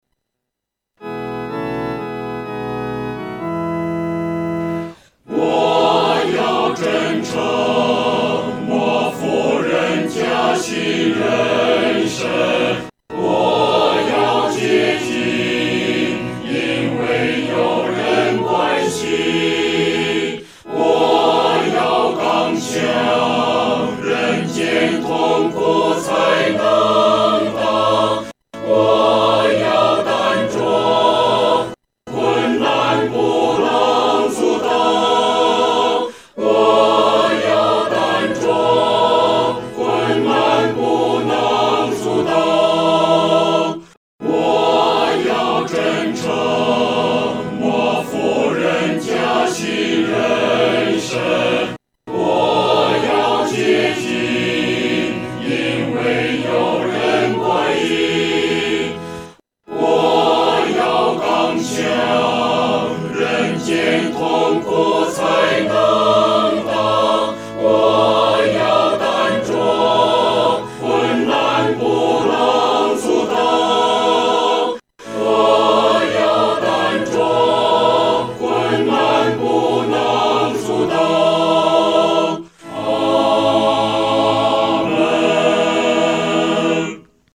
四声部